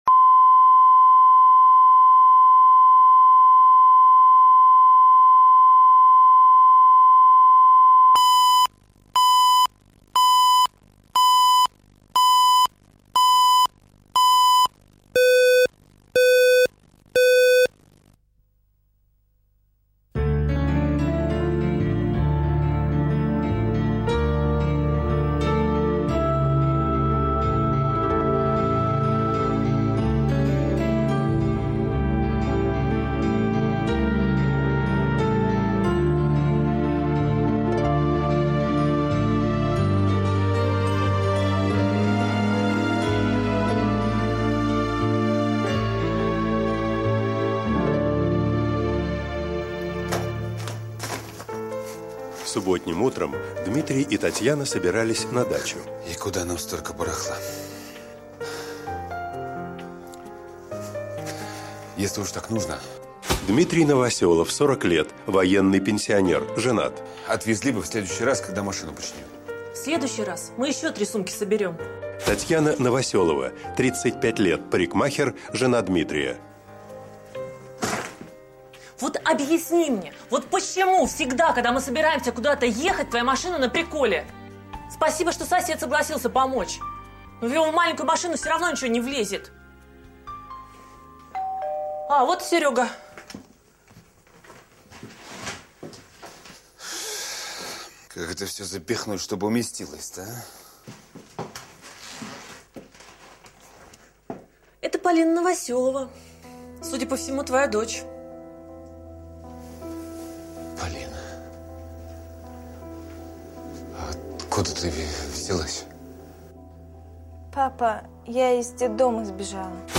Аудиокнига Мой храбрый папа | Библиотека аудиокниг